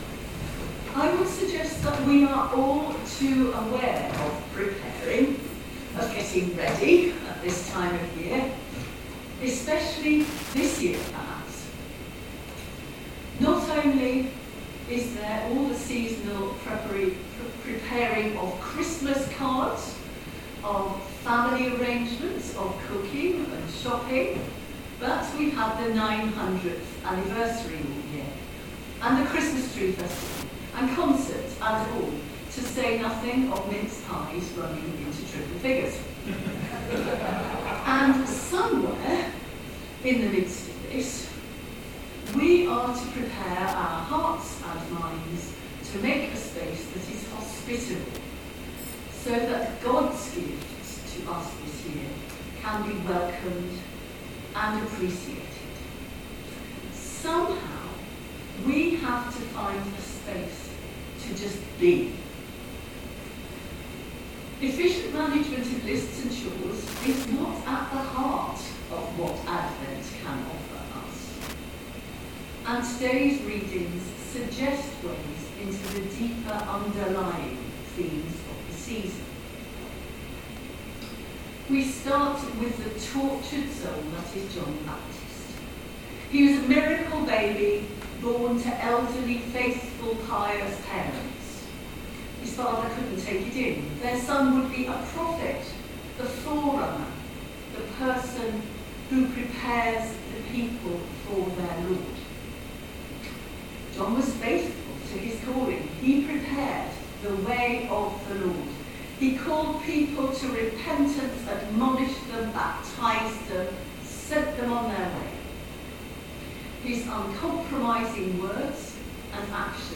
Sermon for third Sunday in Advent.